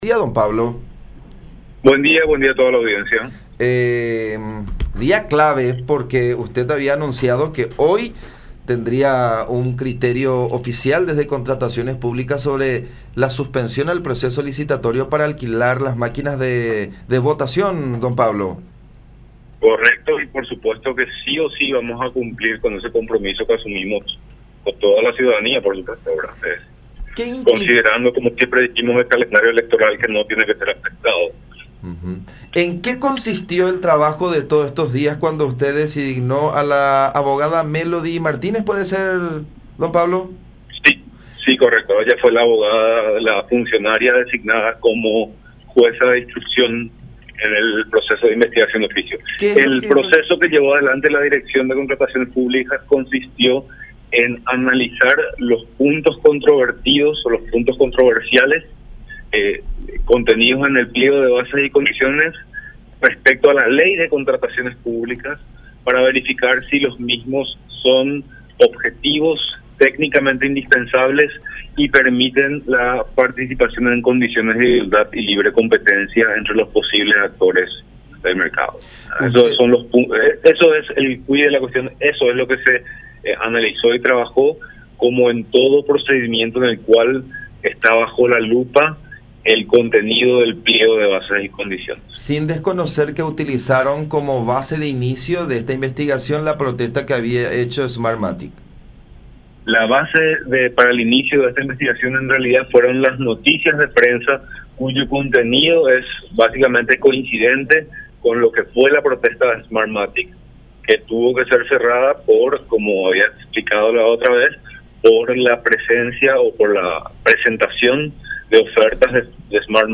“Vamos a cumplir si o si con lo establecido de presentar hoy el dictamen. El proceso consistió en analizar los puntos controversiales en el pliego de bases y condiciones”, explicó Pablo Seitz, titular de la DNCP, en contacto con La Unión.